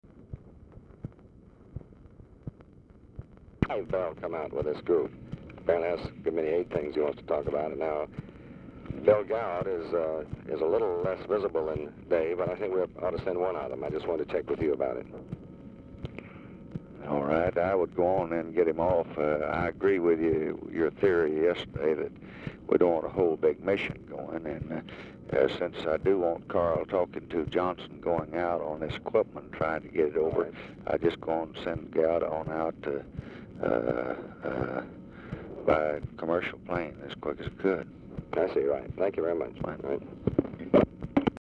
Telephone conversation
RECORDING STARTS AFTER CONVERSATION HAS BEGUN
Format Dictation belt
Oval Office or unknown location